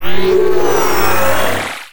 sci-fi_power_up_12.wav